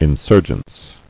[in·sur·gence]